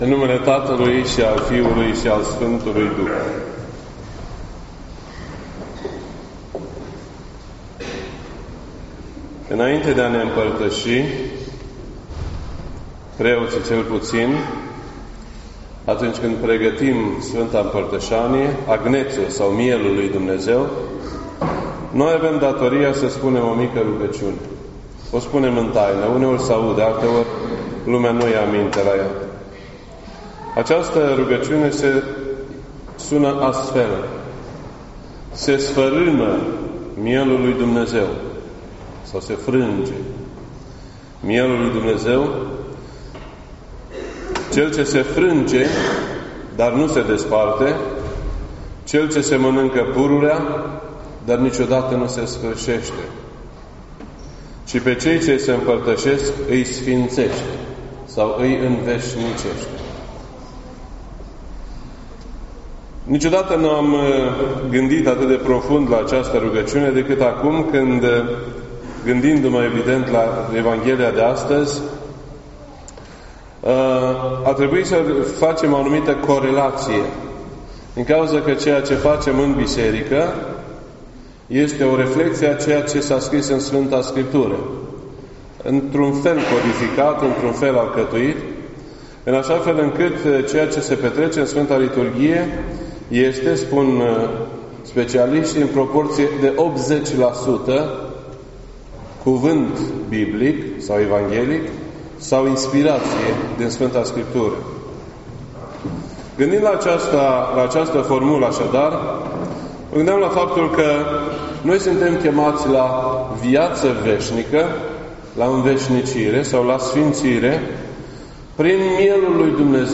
This entry was posted on Sunday, October 29th, 2017 at 1:55 PM and is filed under Predici ortodoxe in format audio.